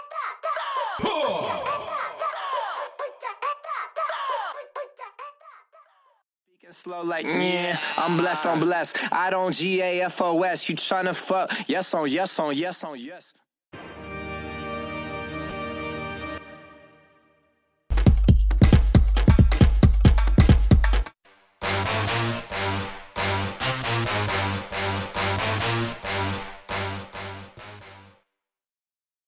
Studio Backing Vocals & SFX Stem
Studio Brass Stem
Studio Bright Synths Stem
Studio Percussion & Drums Stem